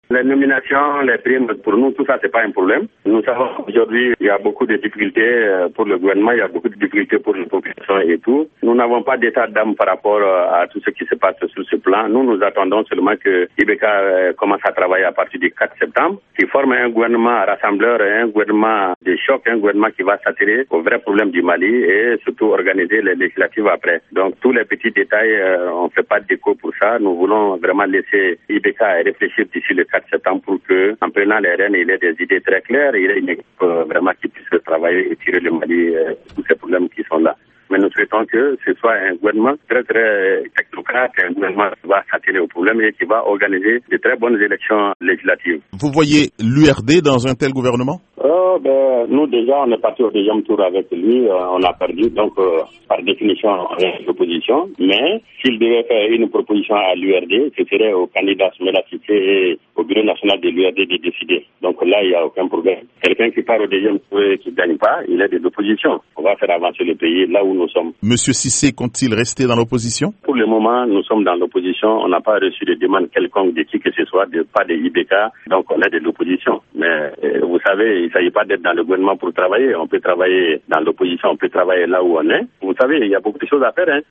La VOA a joint un membre de l’Assemblée, et secrétaire général de l’URD (le parti de Soumaila Cissé) Gouagnon Coulibaly.